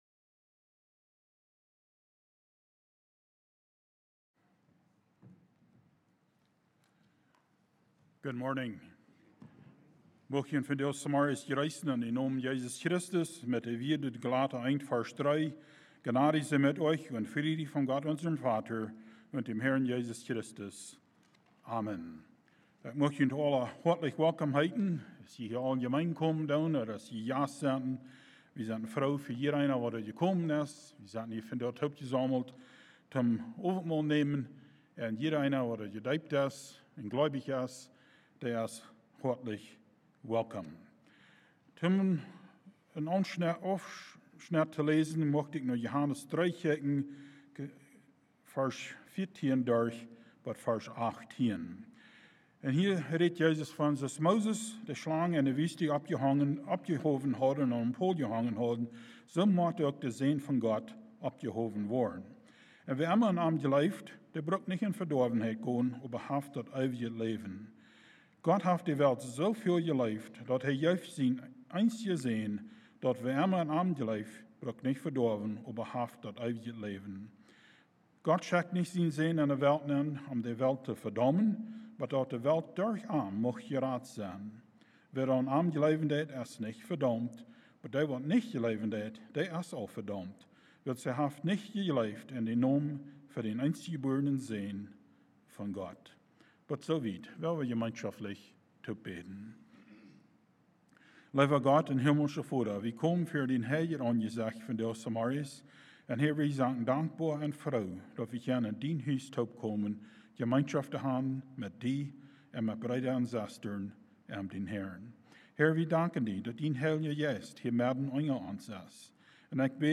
Communion Service